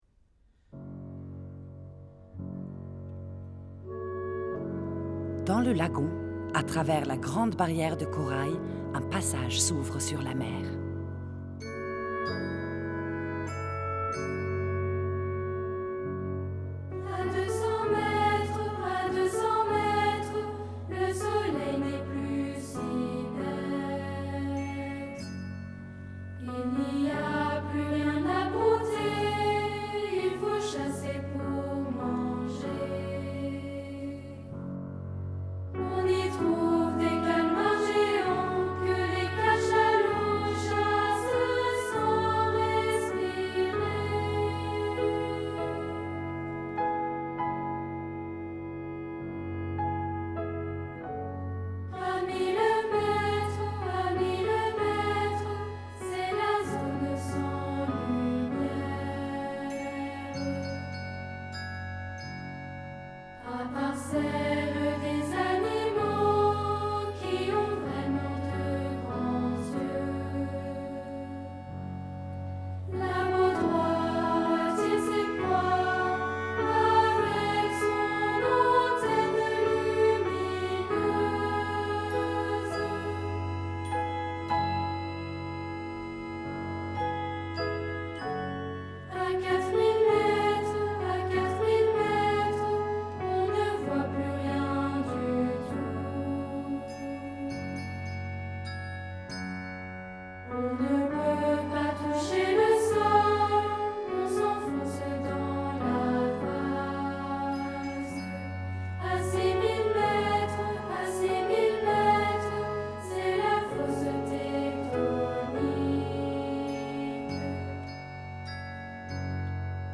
Genre :  ChansonOpéra / Opérette
Style :  Avec accompagnement
Effectif :  UnissonVoix égales
Enregistrement audio Tutti